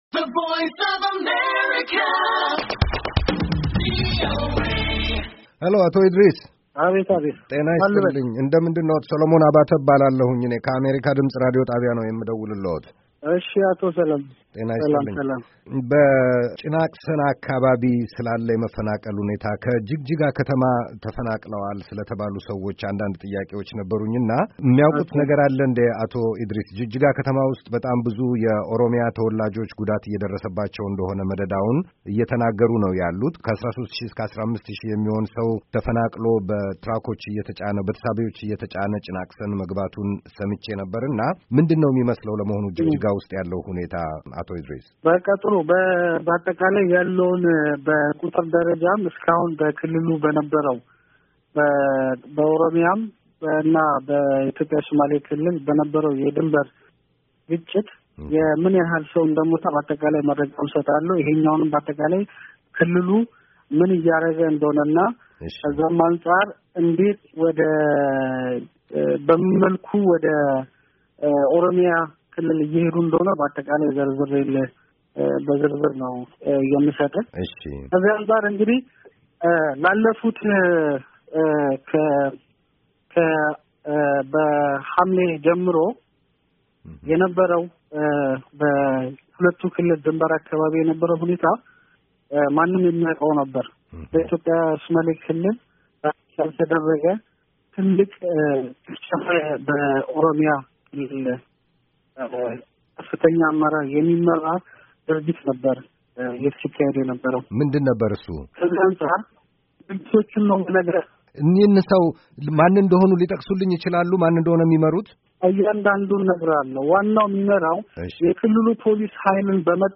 ከሶማሌ ክልል የመንግሥት ኮምዩኒኬሽንስ ጉዳዮች ቢሮ ኃላፊ አቶ እድሪስ እስማኤል ጋር የተደረገ ቃለ ምልልስ